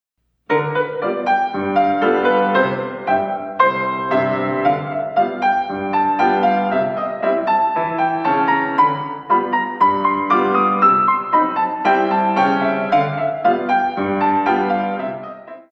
In 2
32 Counts
Battement Jeté